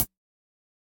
Index of /musicradar/ultimate-hihat-samples/Hits/ElectroHat C
UHH_ElectroHatC_Hit-28.wav